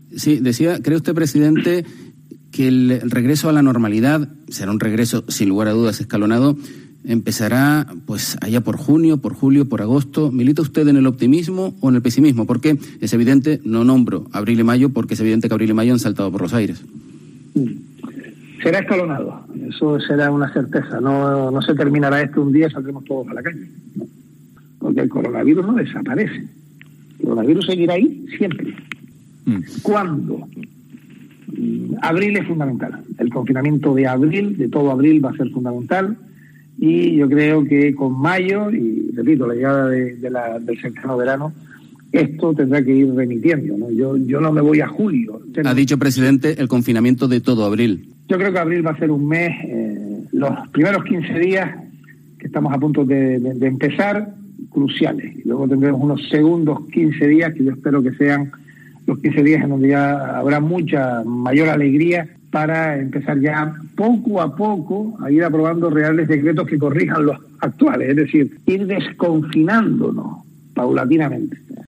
El presidente del Gobierno de Canarias, Ángel Víctor Torres, se muestra en una entrevista en COPE Canarias, razonablemente satisfecho de cómo está evolucionando esta crisis del coronavirus en las Islas, aunque matiza “el confinamiento de todo el mes de abril será fundamental, estos primeros quince días, y sobre todo la segunda parte del mes, donde esperamos que se pueda observar una evolución, van a ser claves, además, espero que con la llegada de mayo y las temperaturas del verano los datos mejoren y se pueda ir volviendo a la normalidad”